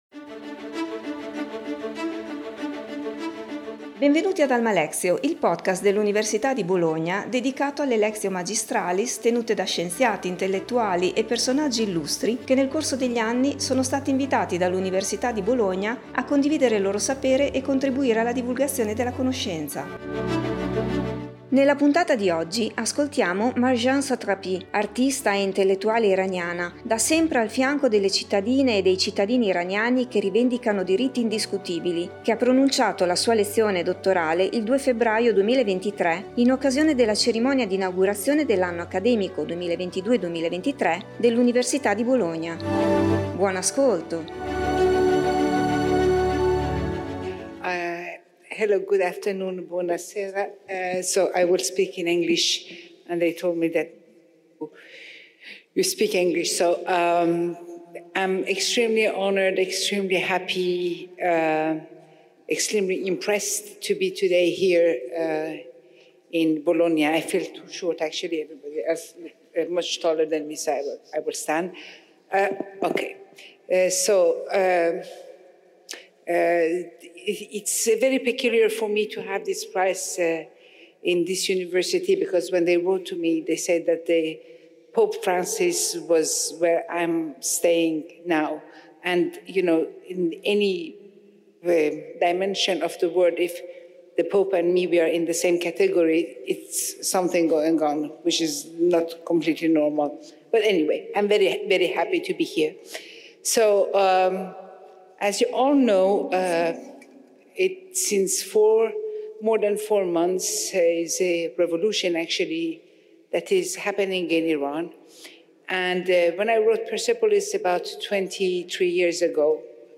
Marjane Satrapi, artista e intellettuale iraniana, da sempre al fianco delle cittadine e dei cittadini iraniani che rivendicano diritti indiscutibili, ha pronunciato la sua lezione dottorale il 2 febbraio 2023 nell’Aula magna di Santa Lucia in occasione della cerimonia di Inaugurazione dell’anno accademico 2022-2023 dell'Università di Bologna.